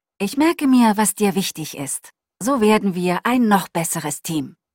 Im Folgenden noch drei Audio-Proben von der deutschen Cortana-Stimme. Wenn die wirklich echt ist und alle Sätze so klingen, ist die Stimme sogar etwas natürlicher als das Pendant von Apple.